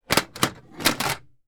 Metal_12.wav